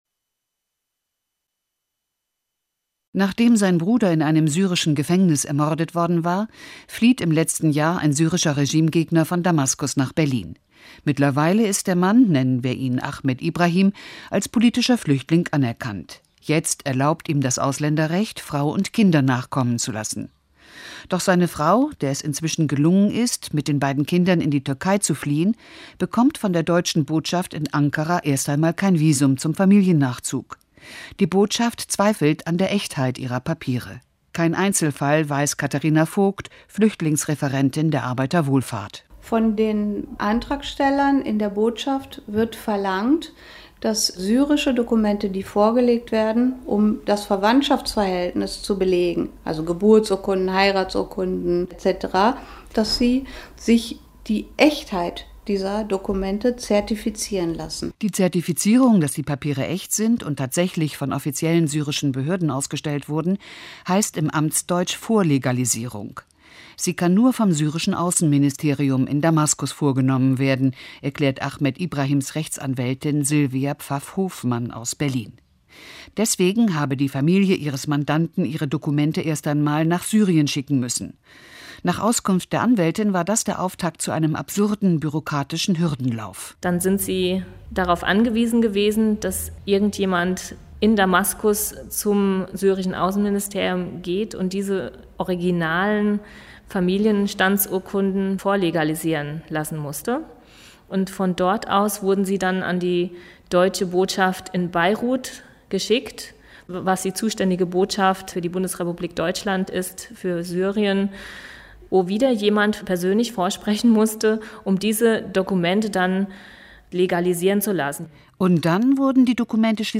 feature des Deutschlandradio Kultur von heute morgen deutlich.